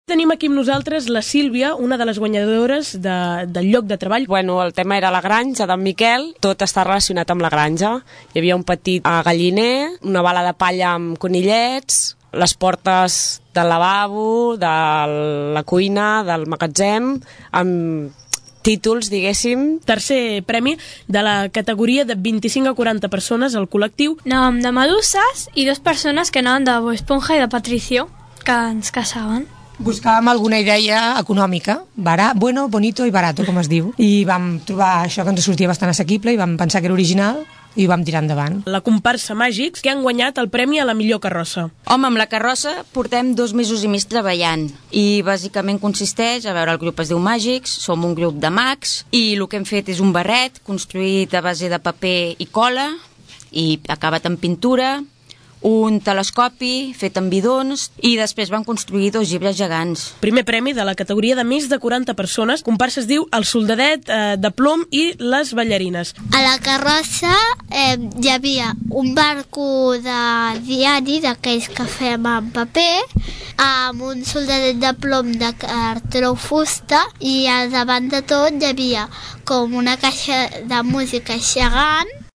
Escoltem el que explicaven alguns dels premiats en el Carnestoltes de Tordera 2015, aquest dissabte a la tarda, en declaracions als micròfons d’aquesta emissora.